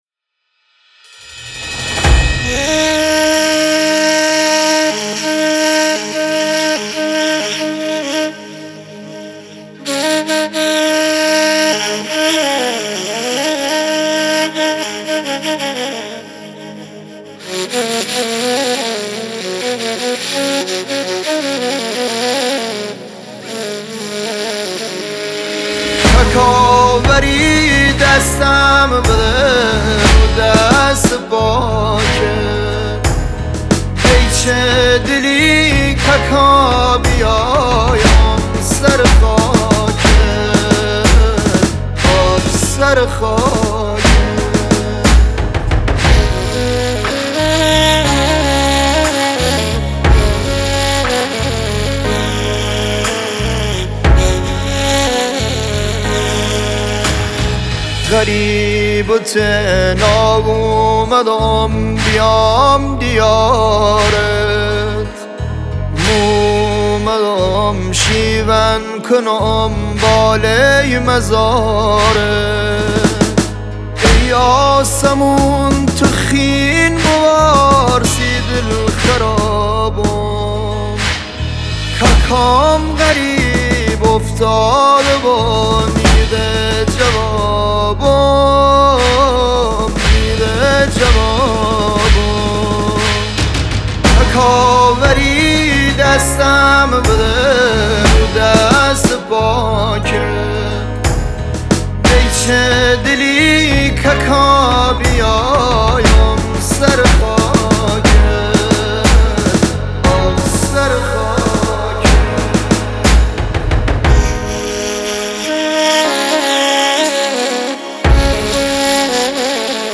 دانلود مداحی لری
نوحه و مداحی